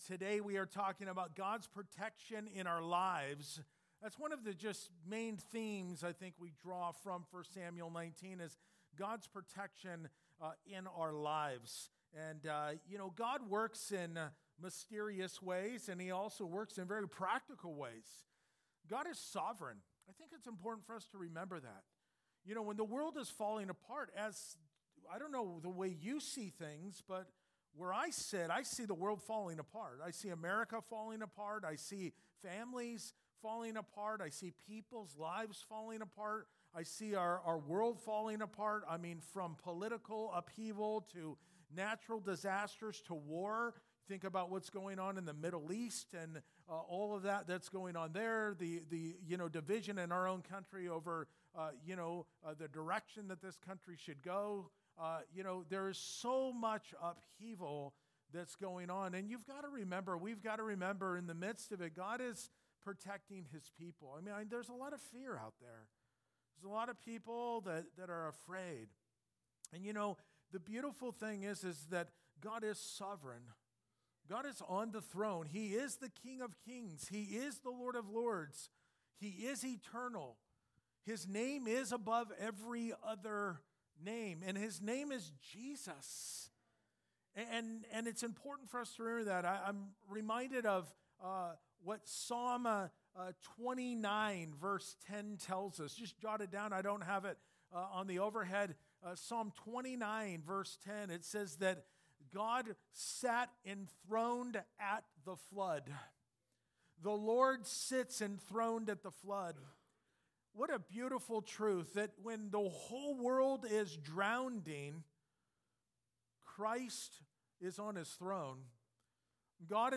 Gods+Protection+in+our+Lives+2nd+Service.mp3